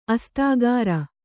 ♪ astāgāra